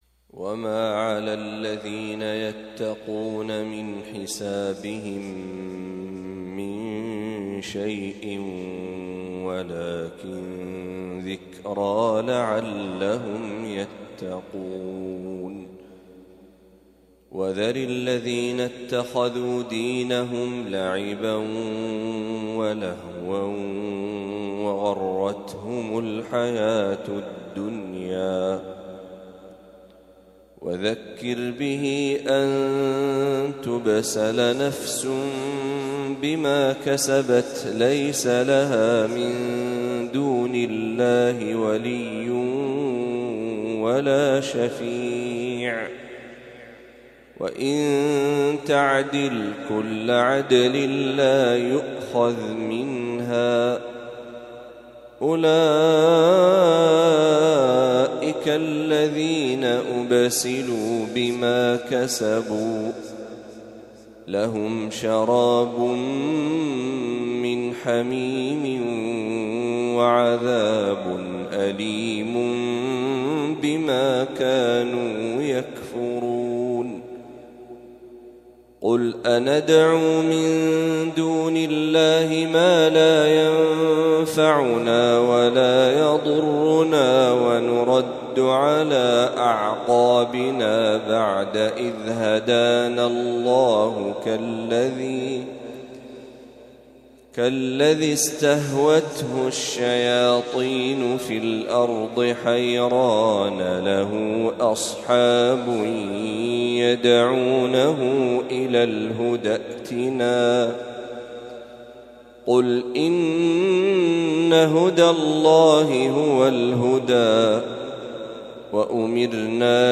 ما تيسر من سورة الأنعام | فجر الأحد ١٩ ربيع الأول ١٤٤٦هـ > 1446هـ > تلاوات الشيخ محمد برهجي > المزيد - تلاوات الحرمين